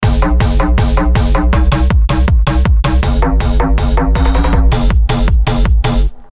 蹦蹦跳跳的循环
描述：这是一个有弹性的循环，有踢腿、高帽、小鼓和苏格兰的唐克。
Tag: 160 bpm Dance Loops Bass Loops 1.06 MB wav Key : Unknown